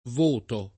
ex voto [lat. HkS v0to] locuz. m.; inv. — più it., anche in questo sign., voto [